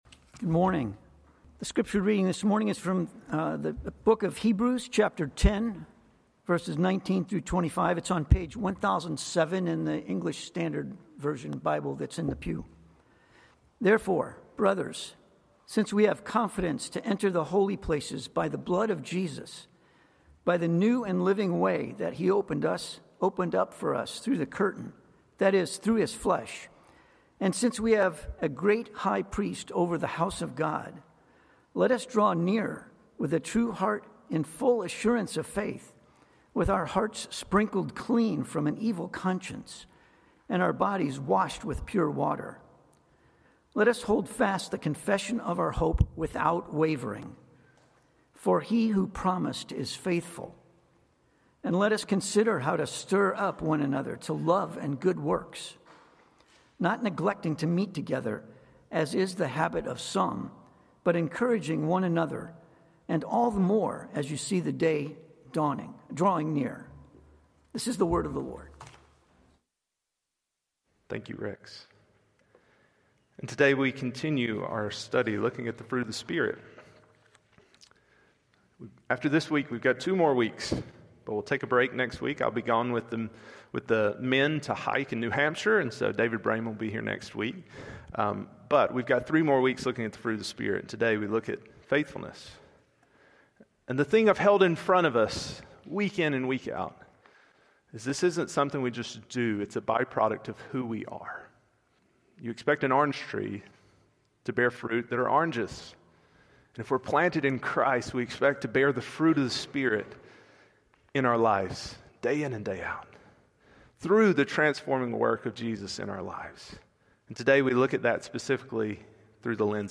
Passage: Hebrews 10:19-25 Sermon